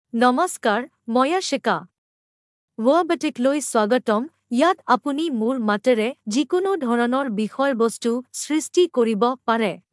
Yashica — Female Assamese (India) AI Voice | TTS, Voice Cloning & Video | Verbatik AI
Yashica is a female AI voice for Assamese (India).
Voice sample
Listen to Yashica's female Assamese voice.
Female